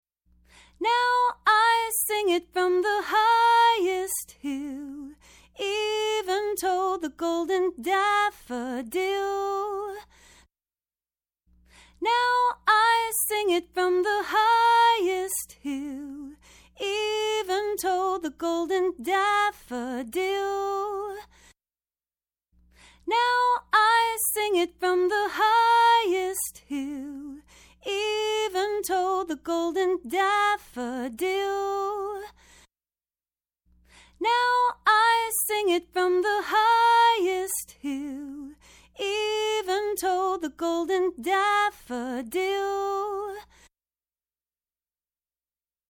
In each of the following listening tests you will hear four playbacks of a musical performance clip. Three of the clips were recorded with vintage Neve 1073 modules and one of the clips was recorded with a Vintech X73 preamp. In each case one mic was passively split to all four preamps so that each preamp could amplify the same exact performance utilizing the same exact microphone. The only variable is the preamps.
Female vocal
female vocal test.mp3